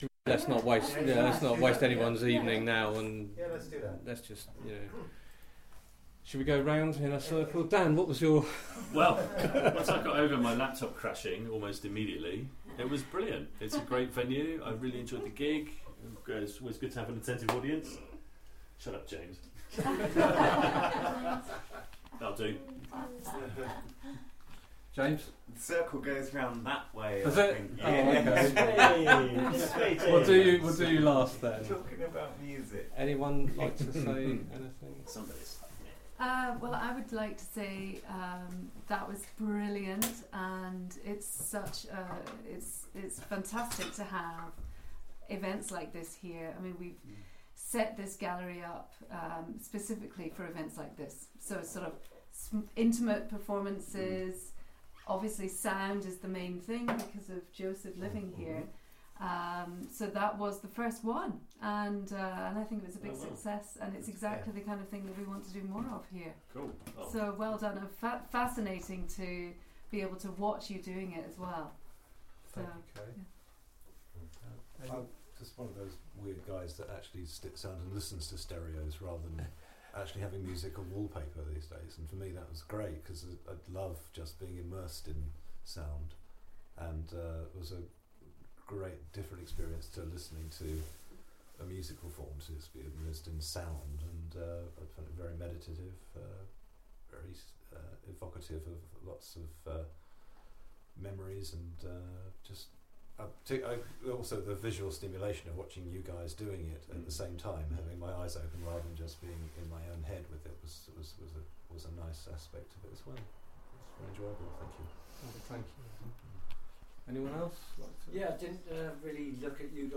Audience Q&A after performances May 13th 2016